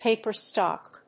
paper+stock.mp3